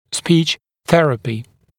[spiːʧ ‘θerəpɪ][спи:ч ‘сэрэпи]логопедичское лечение, лечение дефектов речи